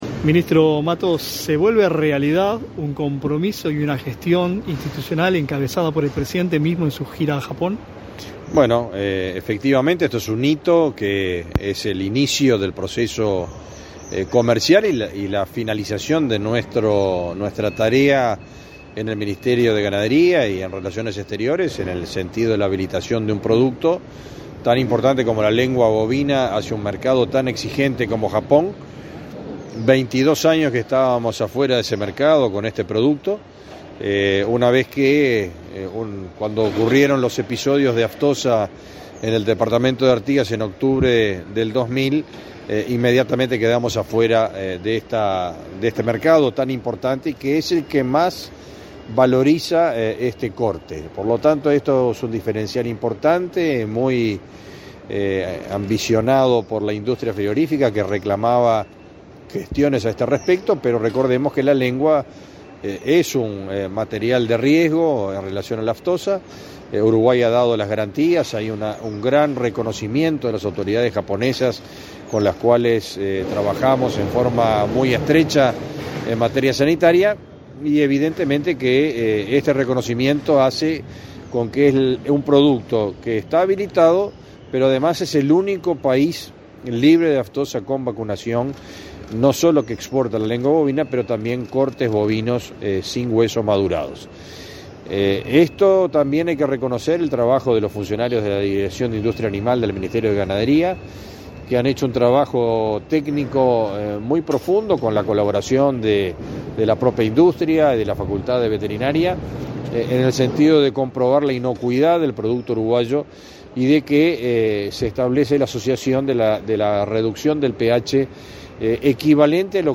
Entrevista al ministro de Ganadería, Agricultura y Pesca, Fernando Mattos